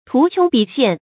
tú qióng bǐ xiàn
图穷匕见发音
成语正音 见，不能读作“jiàn”。